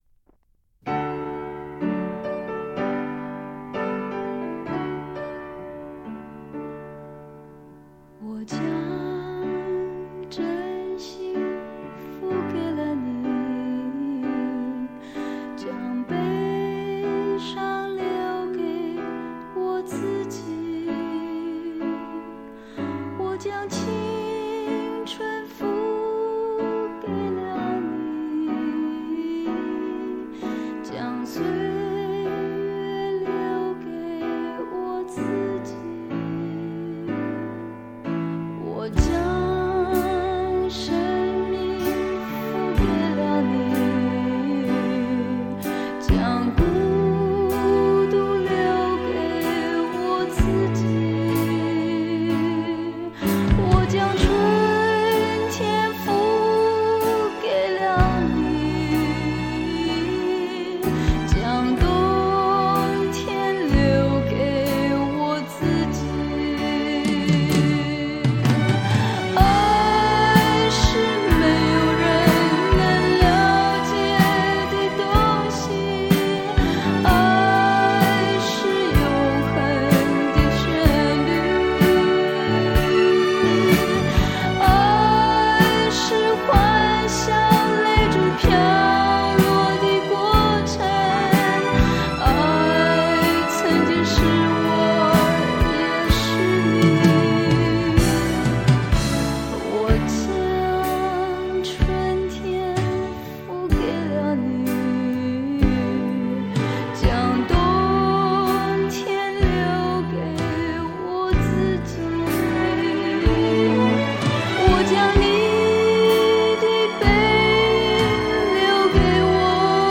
以速度一统的律动行进
反以较为内敛的形式吟哦出这些令人动容的经典曲目